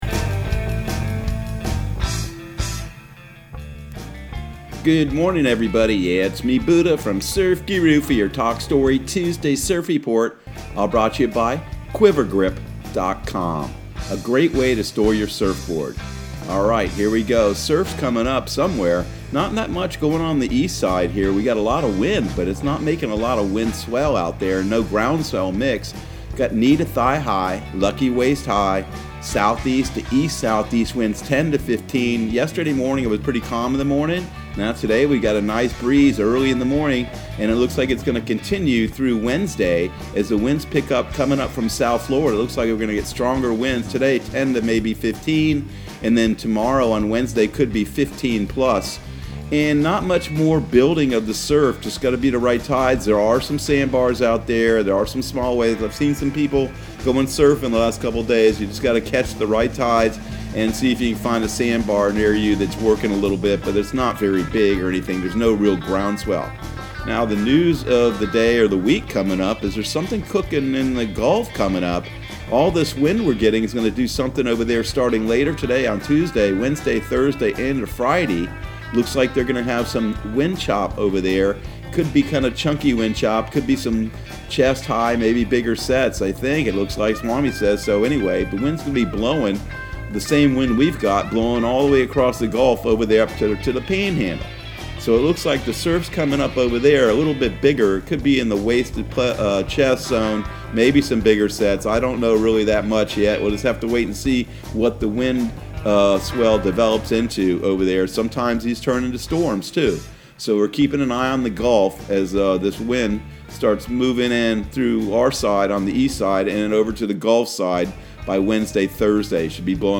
Surf Guru Surf Report and Forecast 07/21/2020 Audio surf report and surf forecast on July 21 for Central Florida and the Southeast.